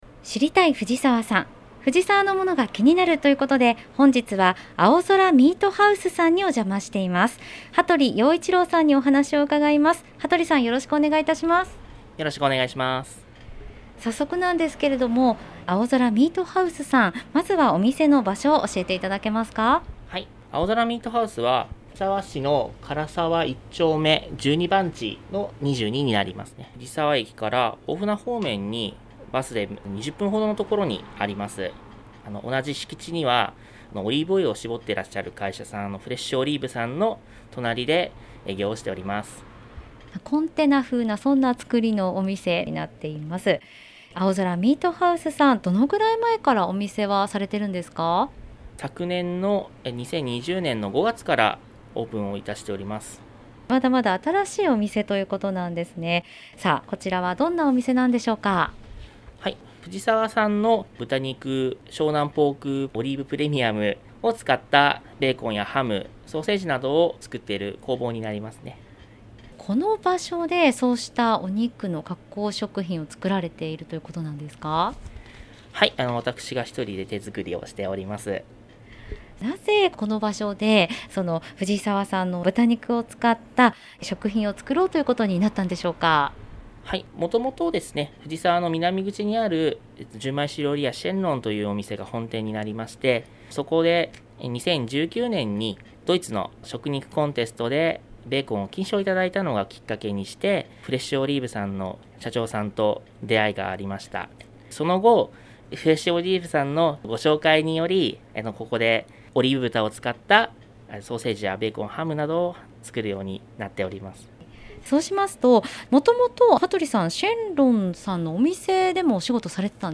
令和3年度に市の広報番組ハミングふじさわで放送された「知りたい！藤沢産」のアーカイブを音声にてご紹介いたします。